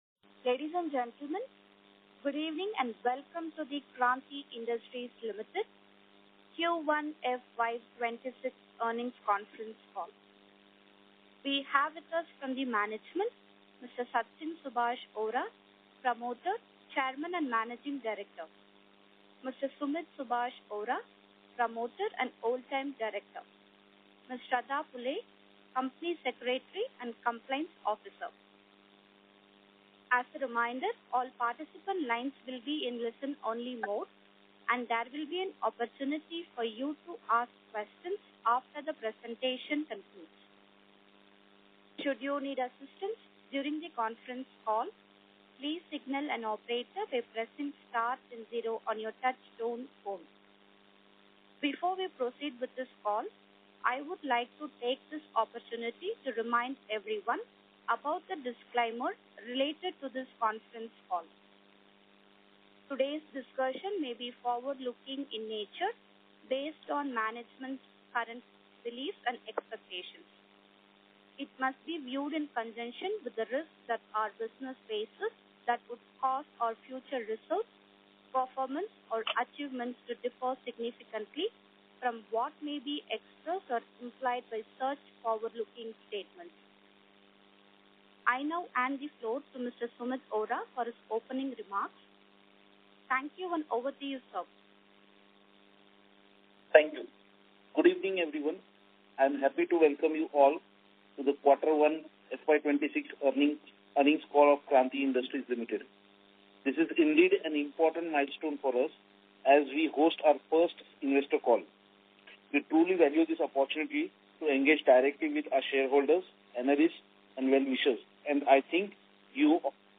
Concalls
Concall-Audio-Q1FY26-Kranti.mp3